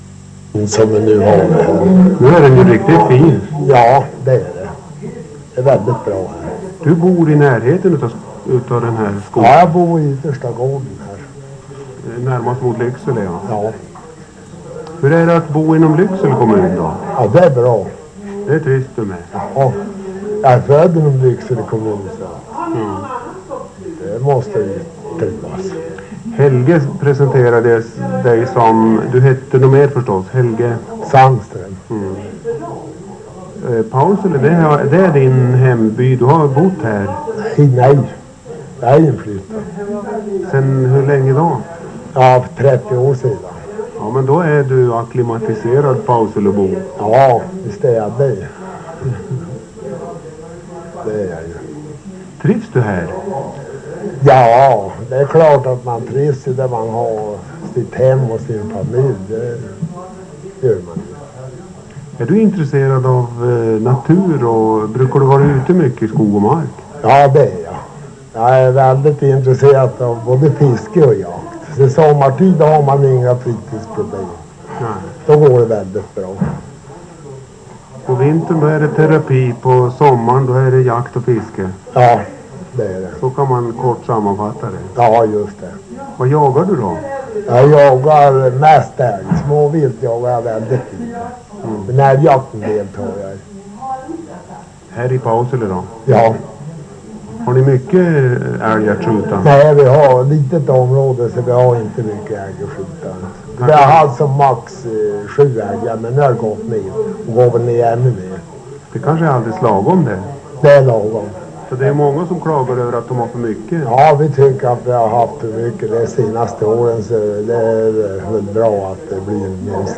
Fem personer berättar om Pausele